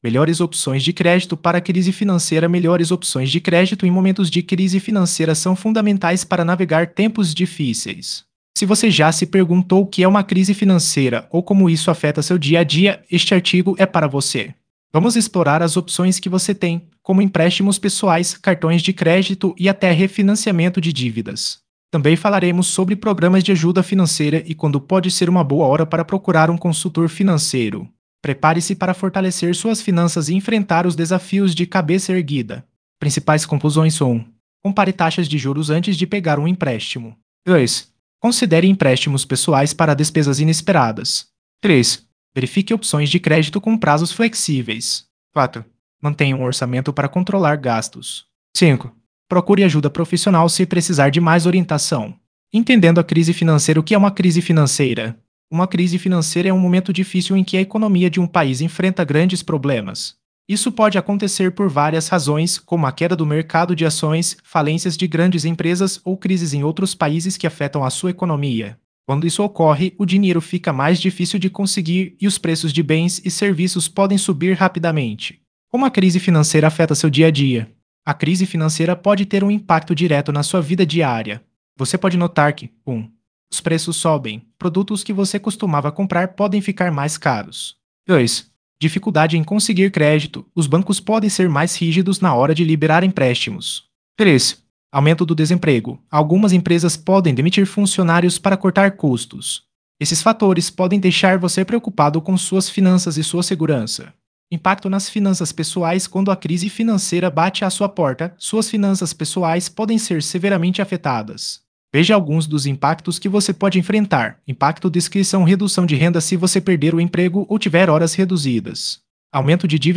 Artigo em áudio e texto abordando opções de crédito durante crises financeiras, incluindo empréstimos pessoais, financiamentos e refinanciamento. Discute impactos econômicos, compara taxas de juros e oferece orientações técnicas sobre gestão financeira em períodos de instabilidade.